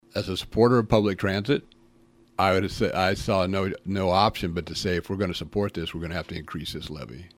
Councilor Jeff Schneider agreed with Nichols